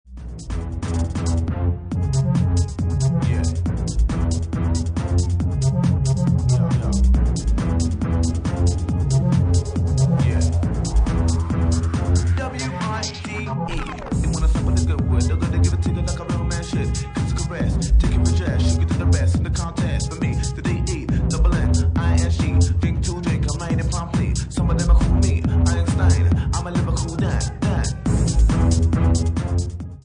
52 bpm